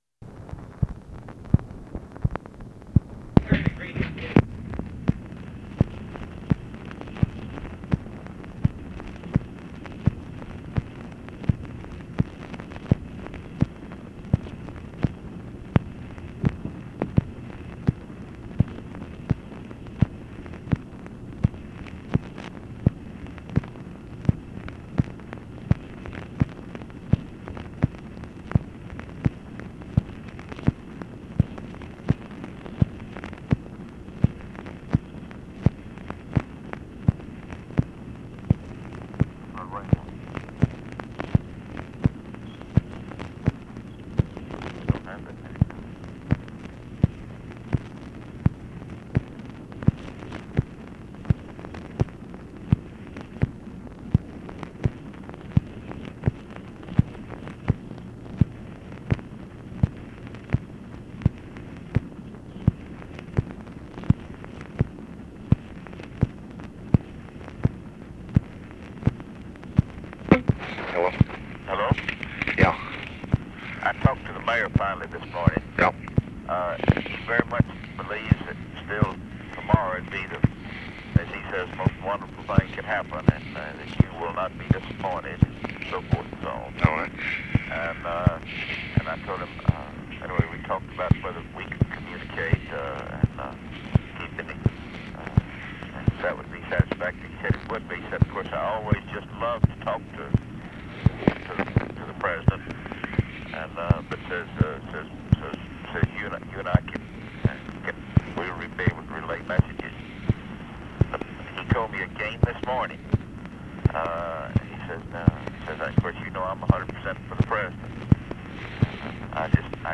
Conversation with MARVIN WATSON, MACHINE NOISE and OFFICE CONVERSATION, August 26, 1968